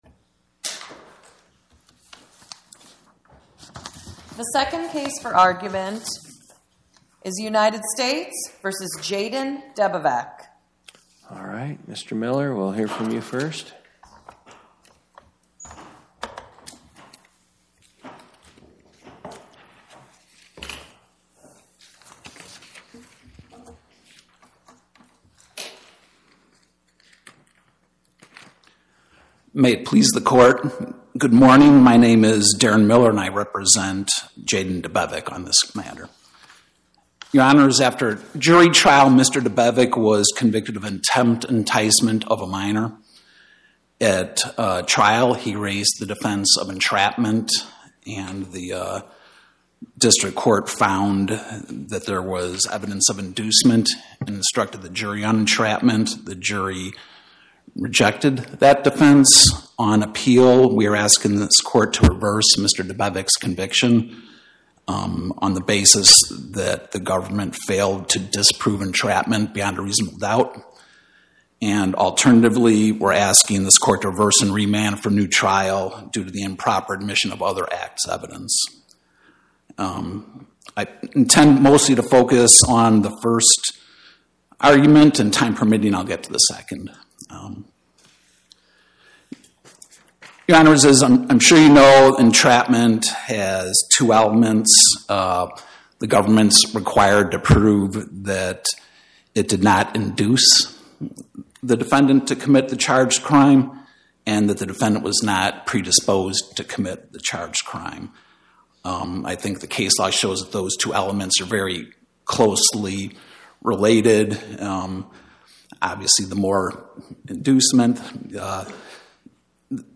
Oral argument argued before the Eighth Circuit U.S. Court of Appeals on or about 02/12/2026